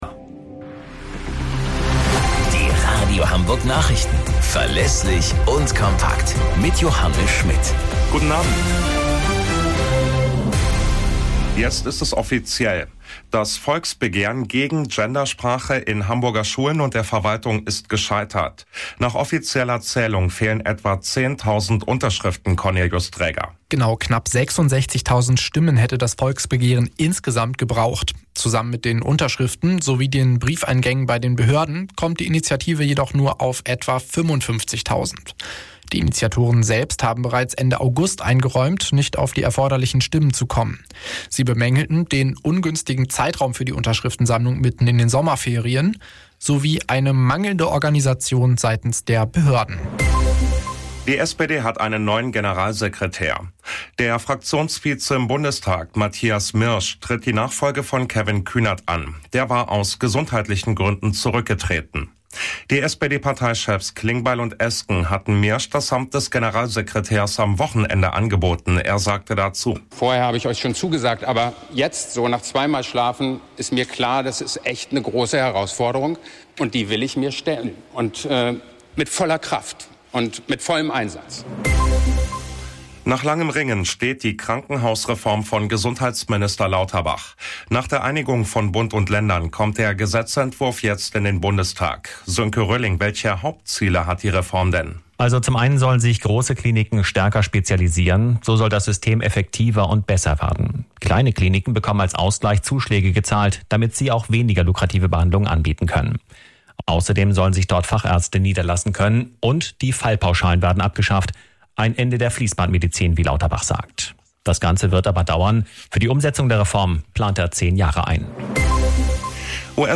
Radio Hamburg Nachrichten vom 09.10.2024 um 03 Uhr - 09.10.2024